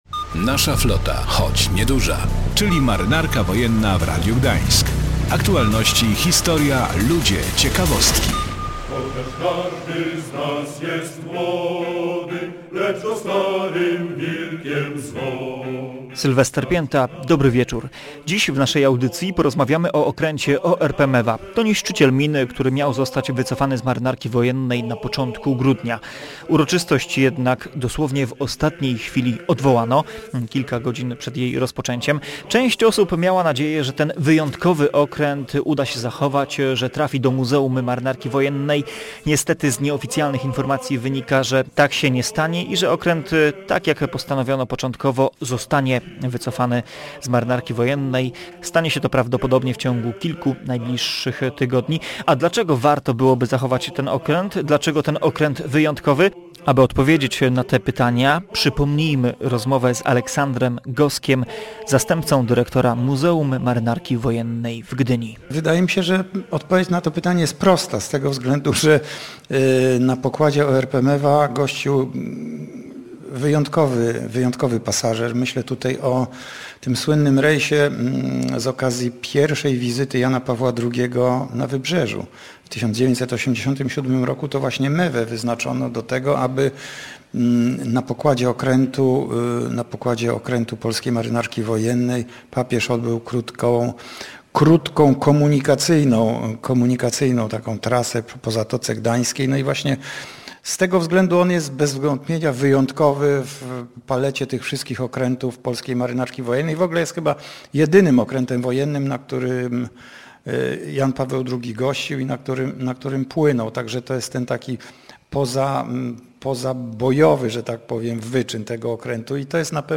/audio/dok3/flota181219.mp3 Tagi: audycje